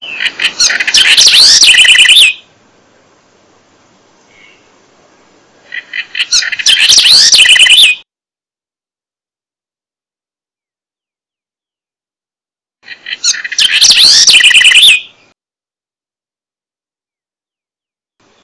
Troglodytes aedon - Ratonera común
ratonera.wav